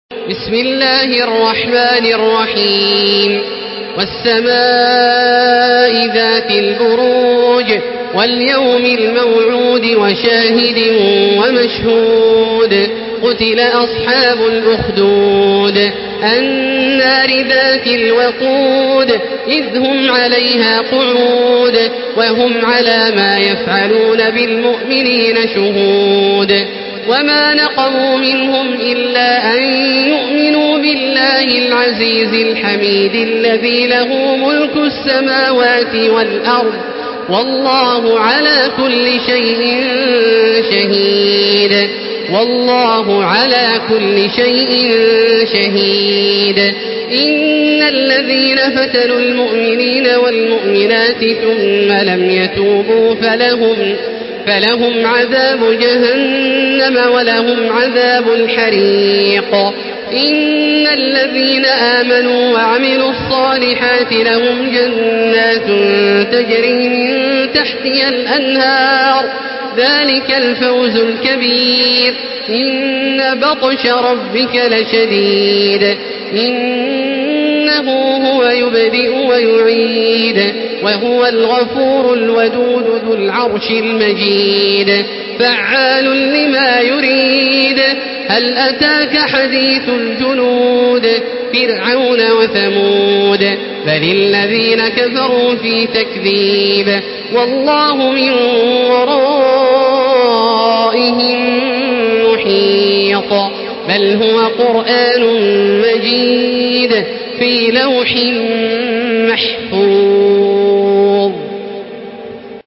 Surah আল-বুরূজ MP3 by Makkah Taraweeh 1435 in Hafs An Asim narration.
Murattal